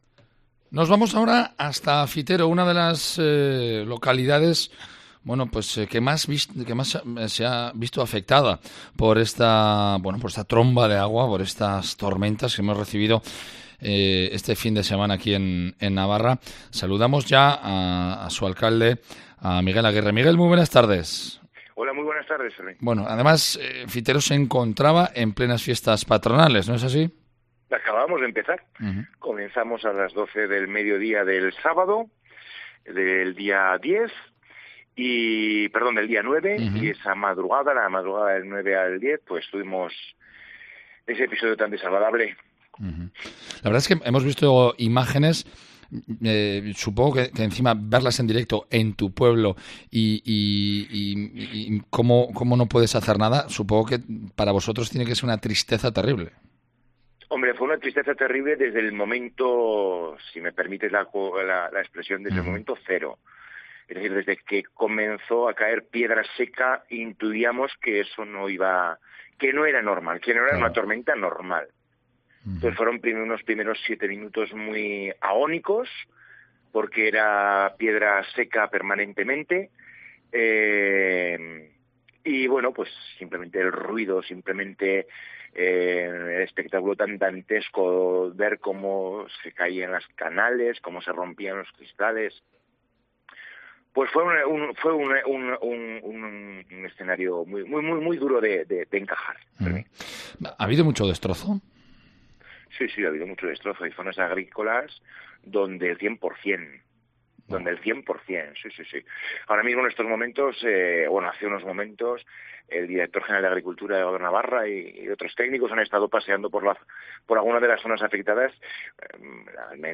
ENTREVISTA CON EL ALCALDE DE FITERO ,MIGUEL AGUIRRE YANGUAS, SOBRE LA TORMENTA PASADA